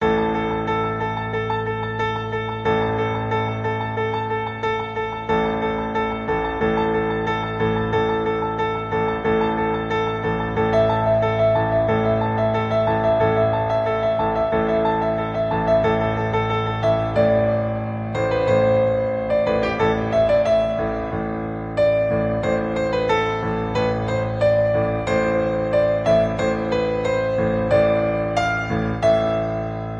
• Key: A Minor
• Instruments: Piano solo
• Genre: TV/Film, OST, Soundtrack